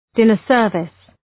Shkrimi fonetik{‘dınər,sɜ:rvıs}